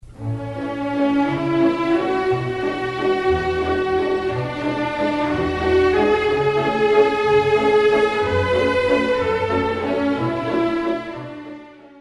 ball